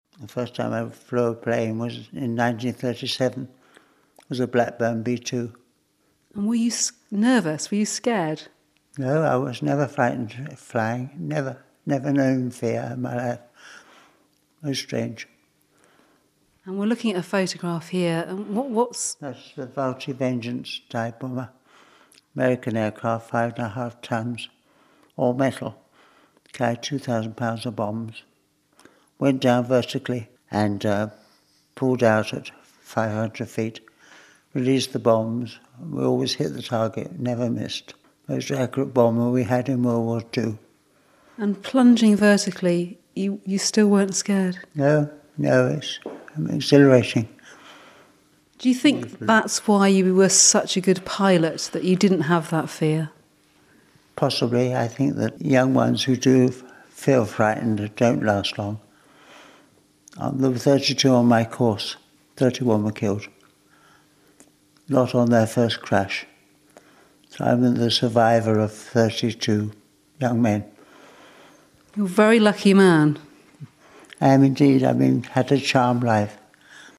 He's been talking to our reporter